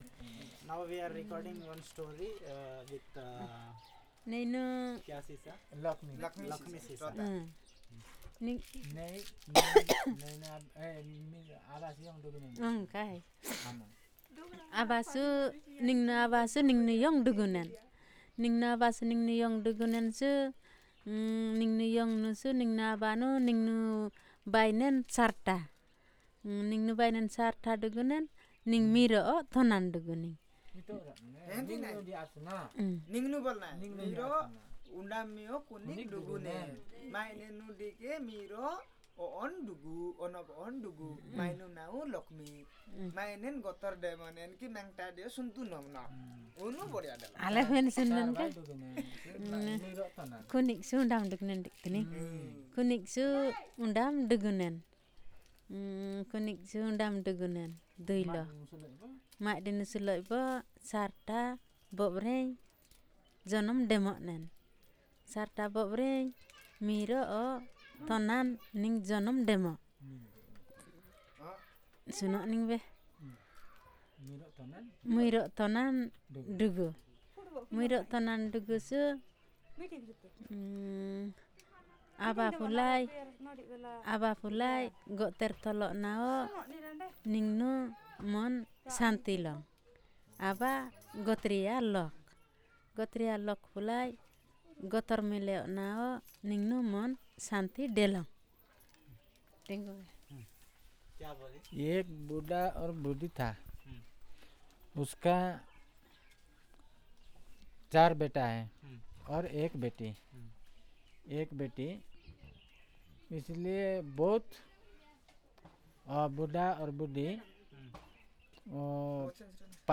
Personal narration on a family story